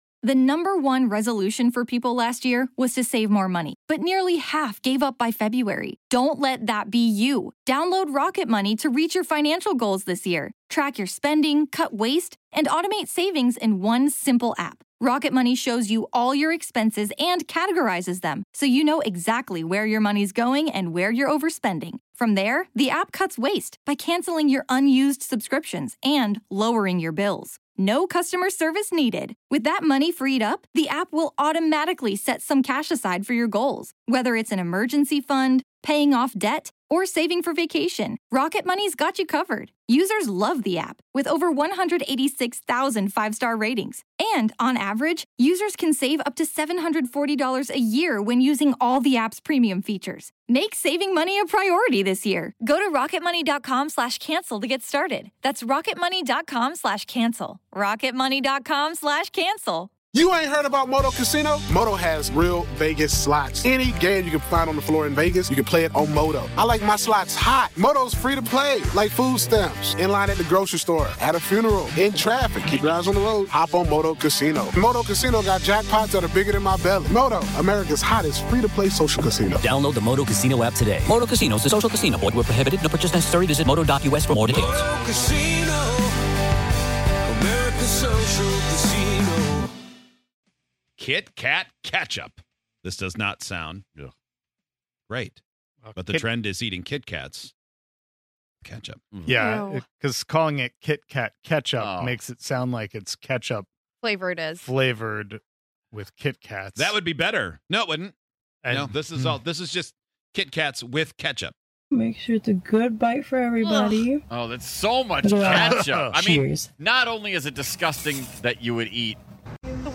On today's show, people are putting ketchup on something that shouldn't have ketchup on it....or should it? It's going viral for a reason so, naturally, we get the ingredients in studio and give it a try.